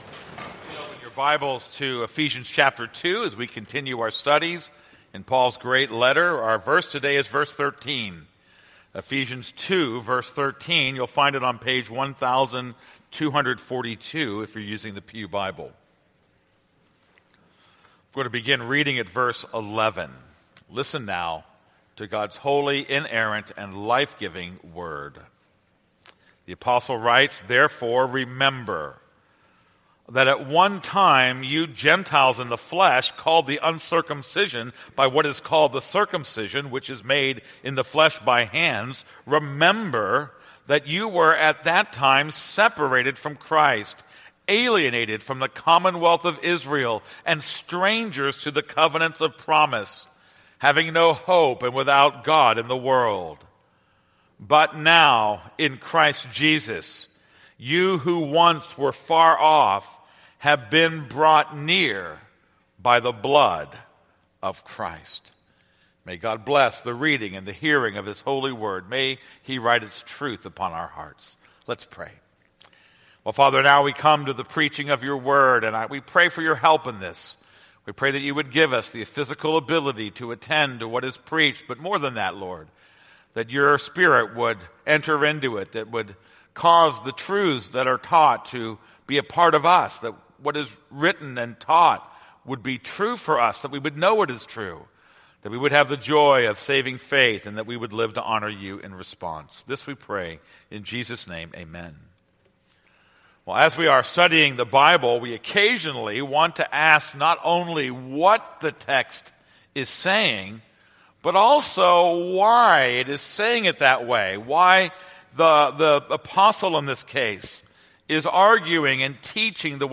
This is a sermon on Ephesians 2:13.